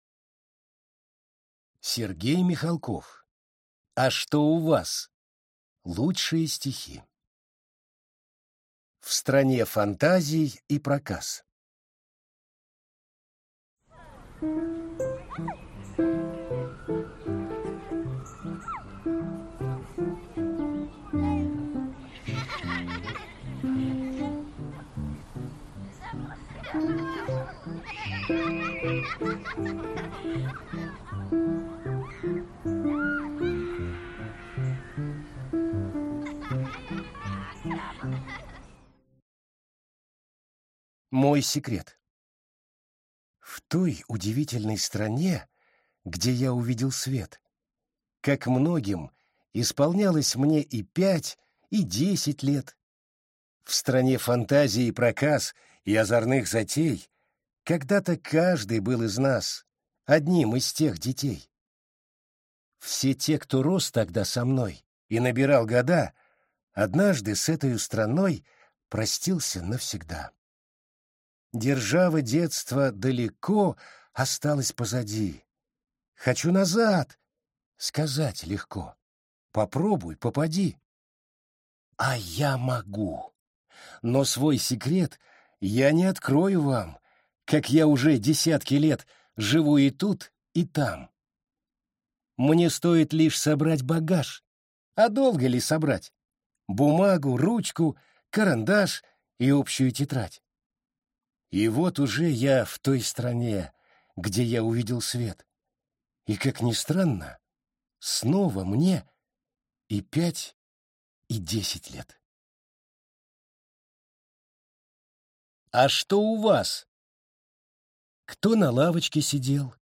Аудиокнига А что у вас? Лучшие стихи | Библиотека аудиокниг